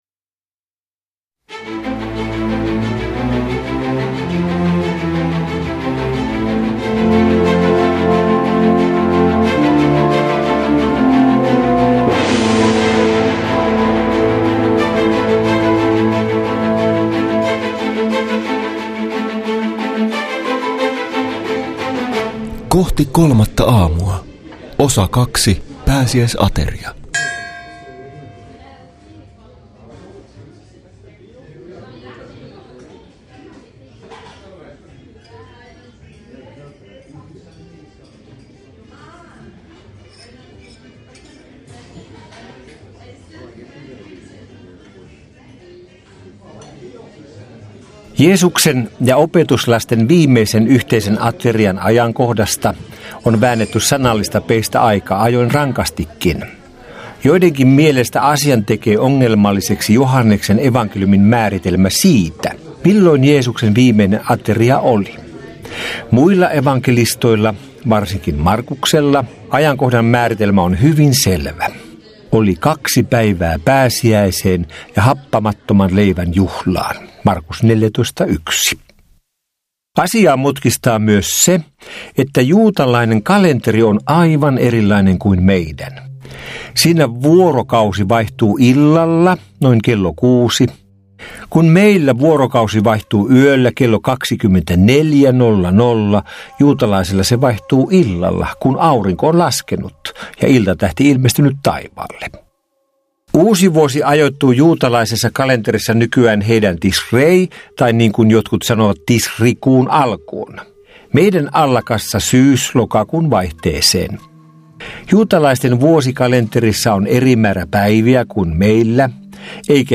Kohti kolmatta aamua ‒ kuunnelmallinen opetussarja Jeesuksen viimeisten päivien vaiheista - Kristityt Yhdessä ry
Pietarin monologit tuovat vankkaan faktatietoon tunteellisen ja inhimillisen sävyn, mikä vie kuuntelijan vaikuttavasti maailman järisyttävimpien tapahtumien keskelle.